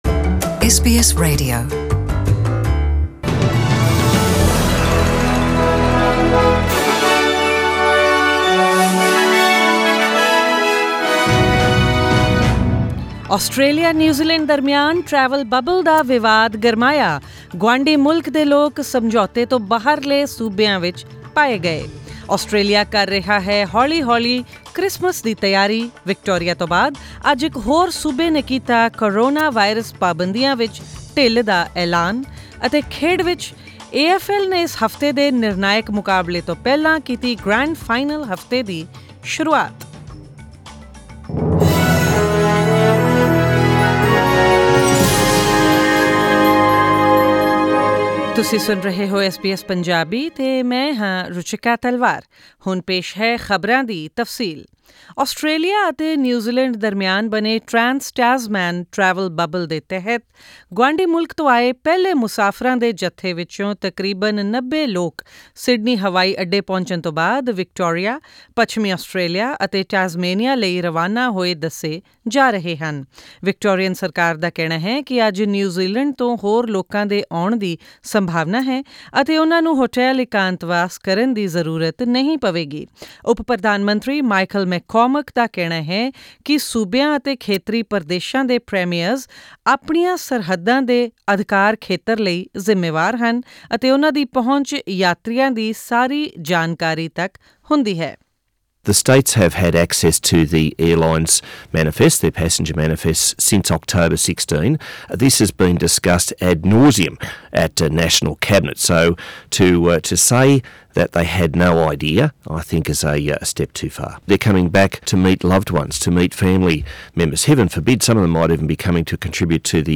In this bulletin...